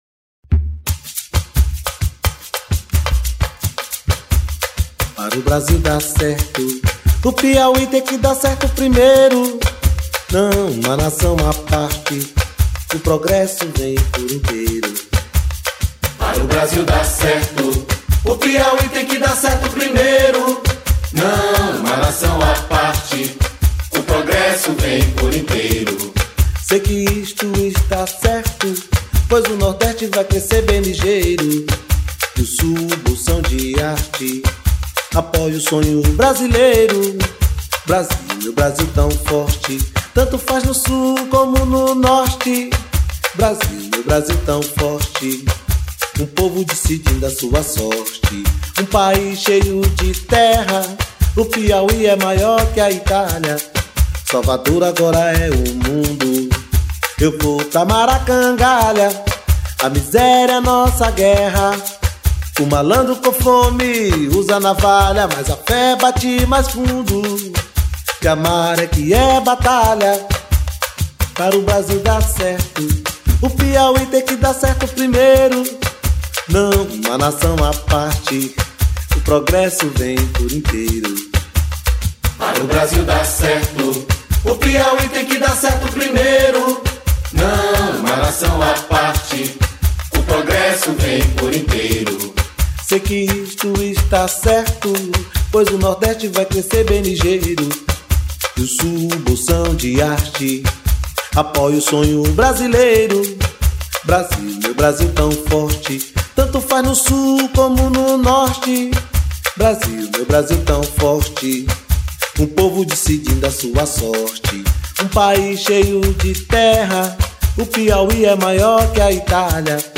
1346   03:29:00   Faixa:     Rock Nacional